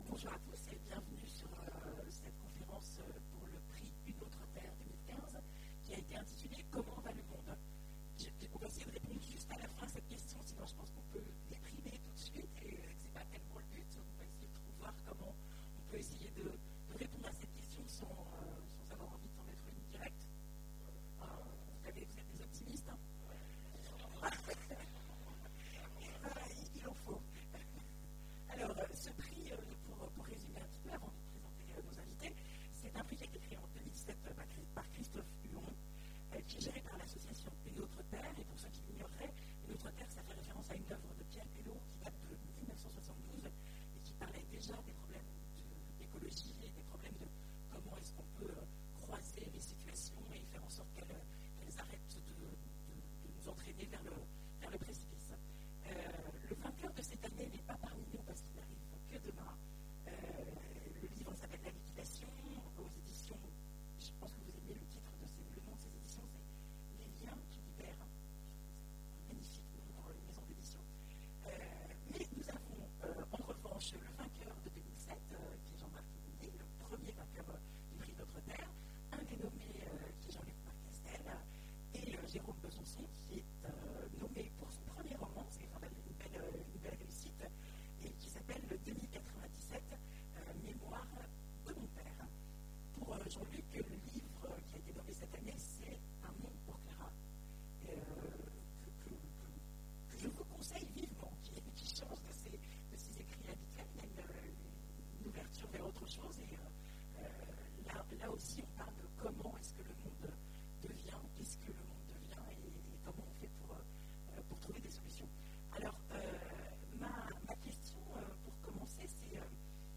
Imaginales 2015 : Conférence Comment va le monde ?